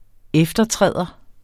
Udtale [ -ˌtʁεˀðʌ ]